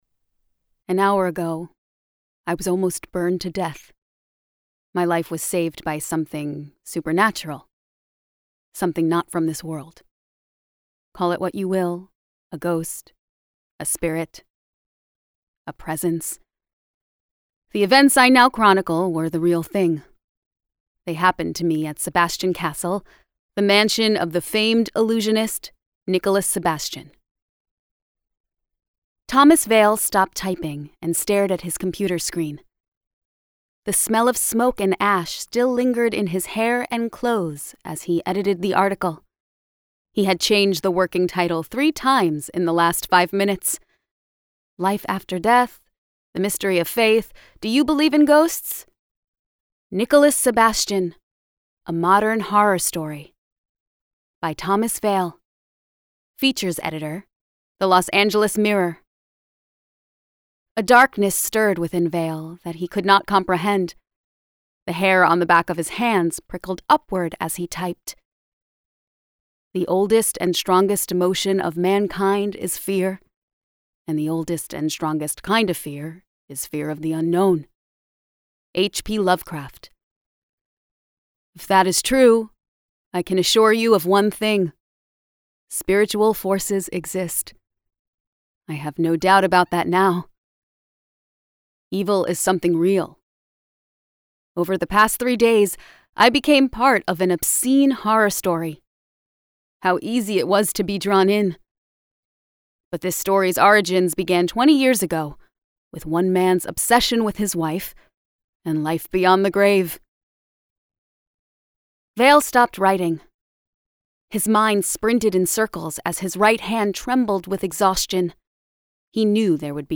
Deadline - Vibrance Press Audiobooks - Vibrance Press Audiobooks